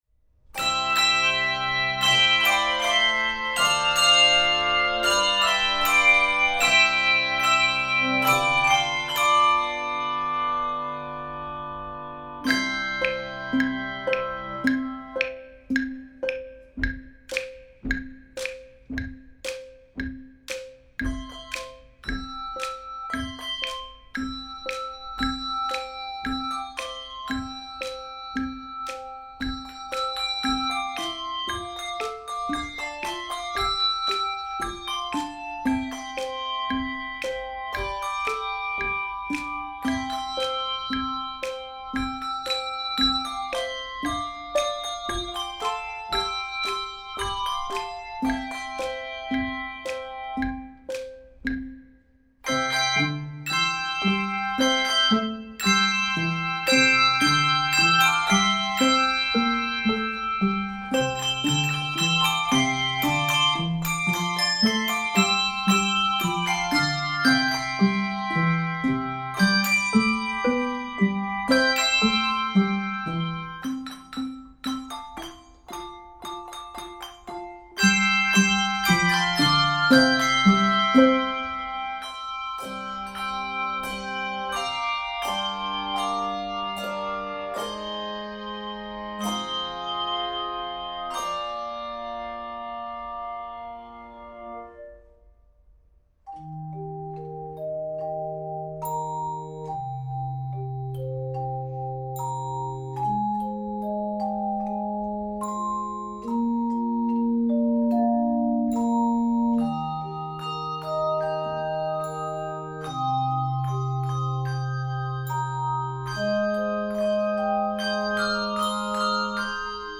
Voicing: 3-5 Octave Handbells and Handchimes